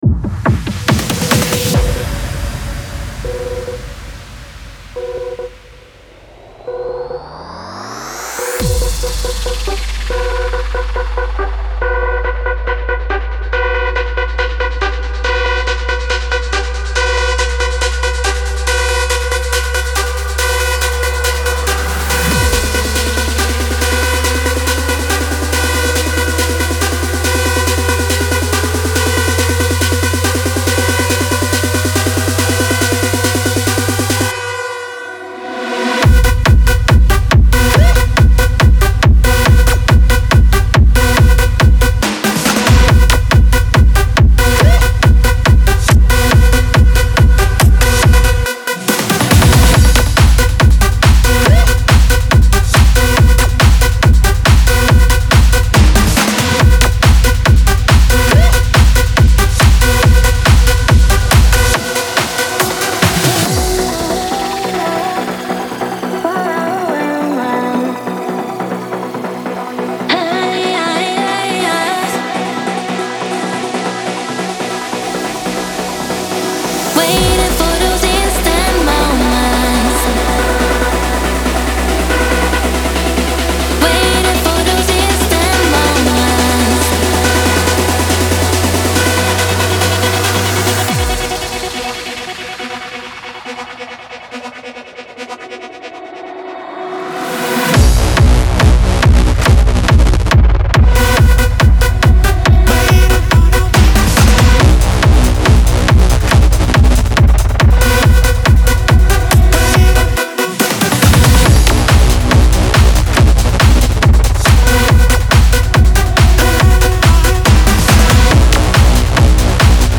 • Жанр: Dance, Techno